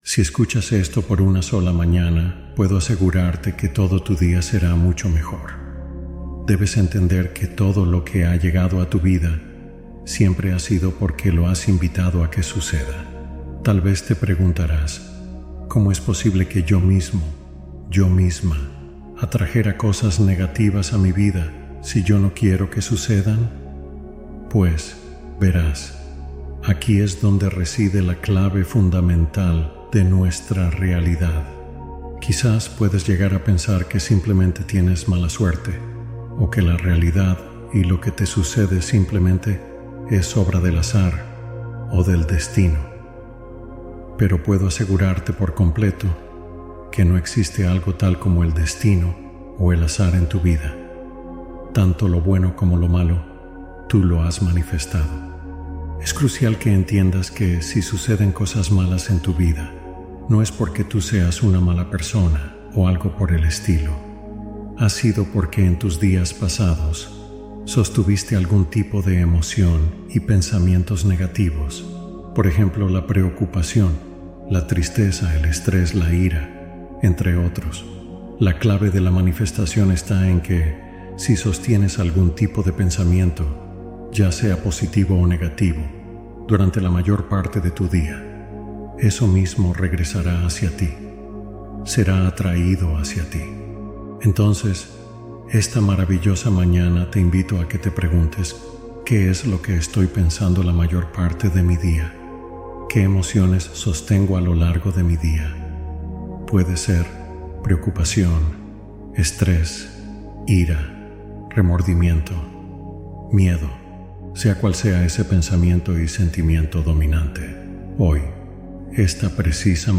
SI ESCUCHAS UNA MAÑANA, TU DÍA SE TRANSFORMARÁ POR COMPLETO | Meditación y Afirmaciones